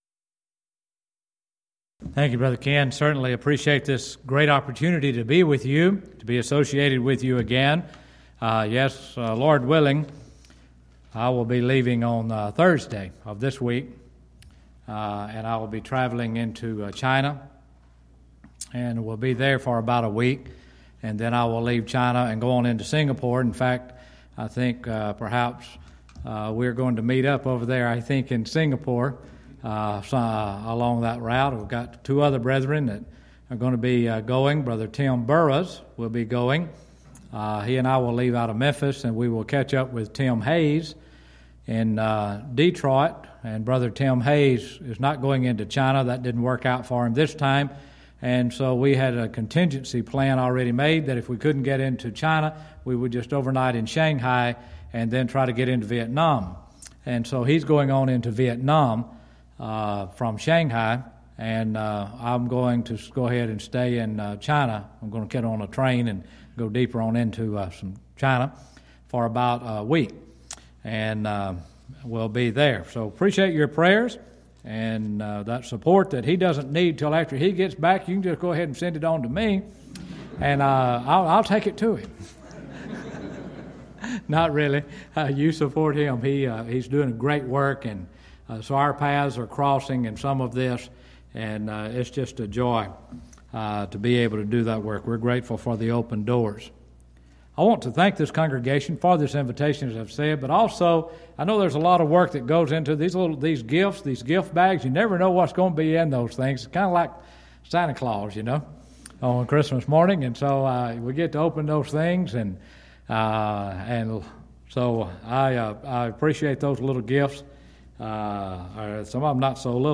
Event: 11th Annual Schertz Lectures Theme/Title: Studies in Daniel